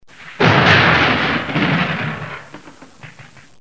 دانلود آهنگ بمب و موشک 14 از افکت صوتی حمل و نقل
دانلود صدای بمب و موشک 14 از ساعد نیوز با لینک مستقیم و کیفیت بالا
جلوه های صوتی